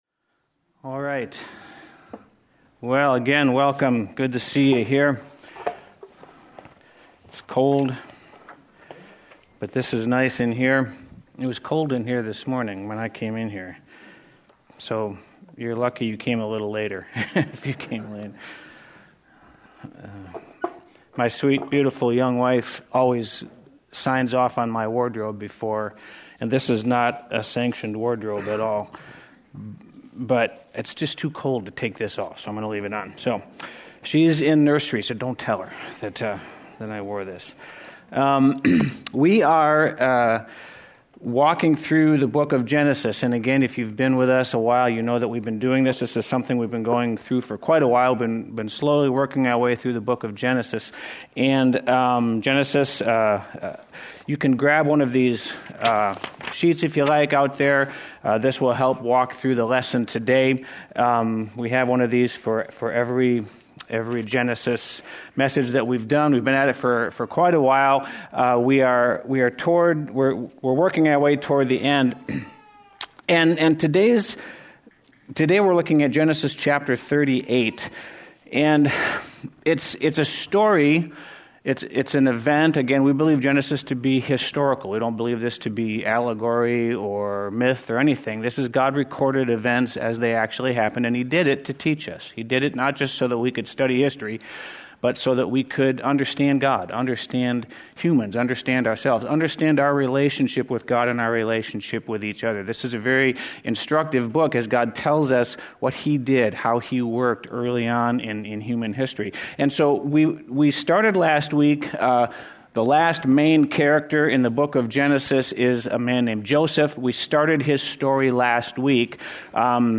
The latest teachings from Cedarcreek Community Church in Eau Claire, Wisconsin